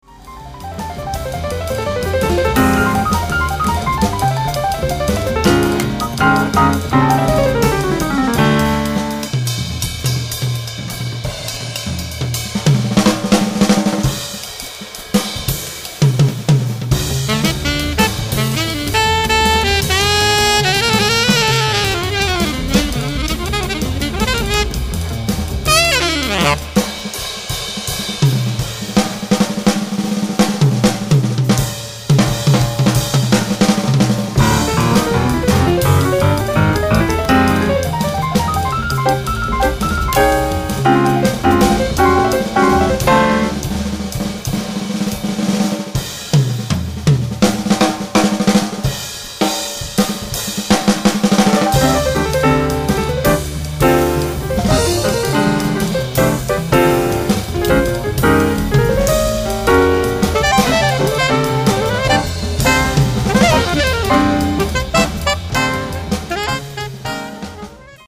sax alto
piano, piano elettrico
contrabbasso, basso elettrico
batteria